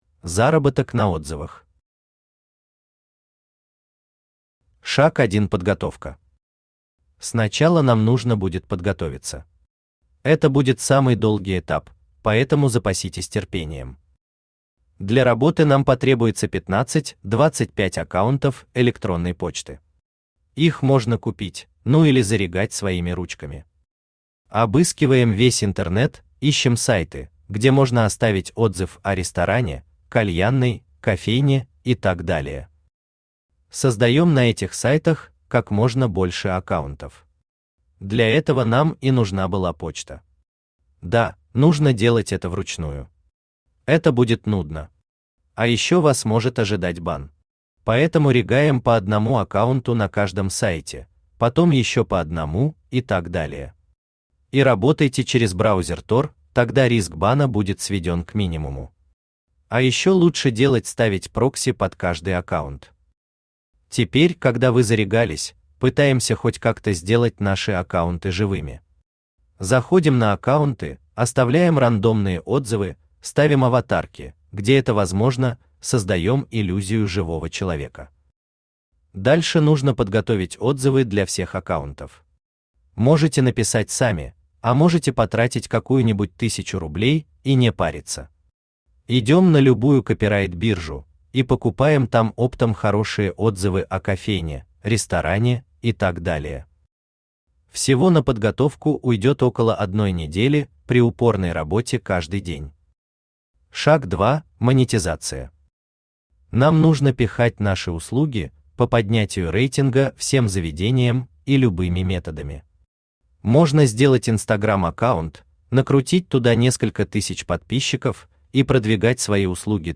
Аудиокнига Схемы заработка 2.0 | Библиотека аудиокниг
Aудиокнига Схемы заработка 2.0 Автор Александр Валерьевич Мошкаров Читает аудиокнигу Авточтец ЛитРес.